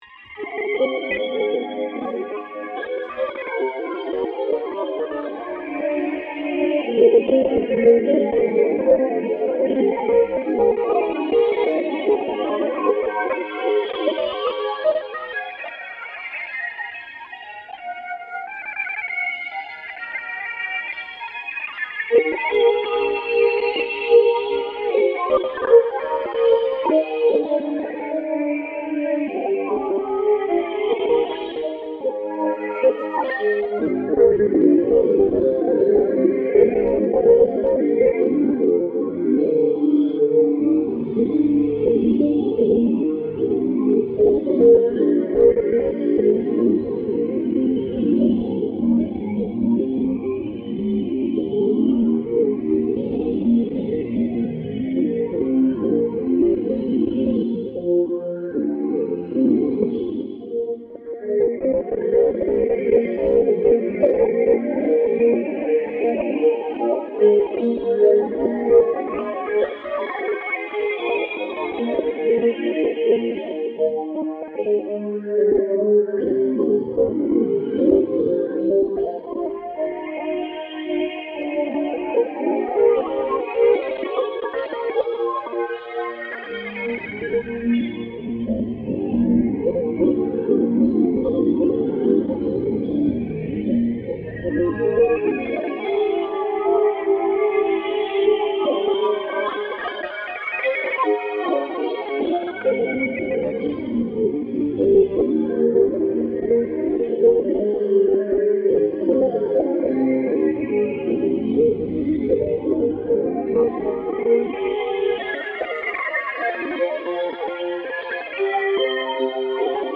This project provides a series of absurdist podcasts about dialogues between humans and Monstera plant to determine the importance of nature in humans’ life during the time of pandemic. I use a specialized device that translates electrical variations in plant into musical pitches. The result is a continuous stream of pleasing music/sound that gives us a sonic window into the secret life of plants.
Sound Art